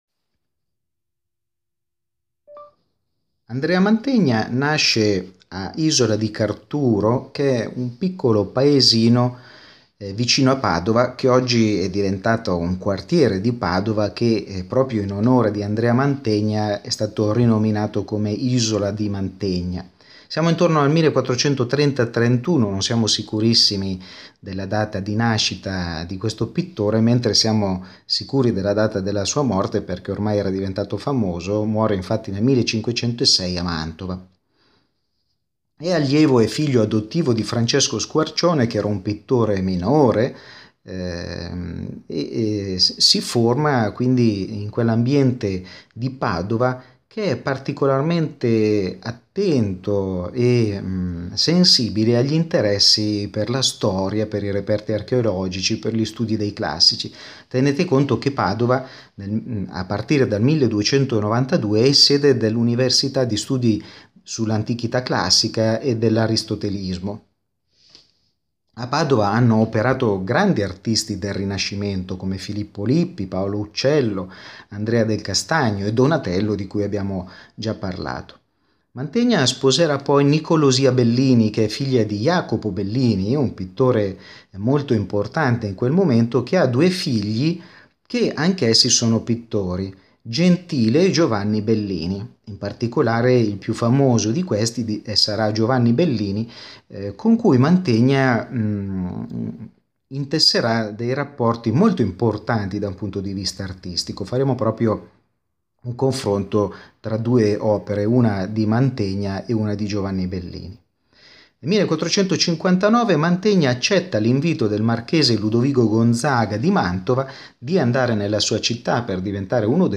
Ascolta la lezione audio dedicata a Mantegna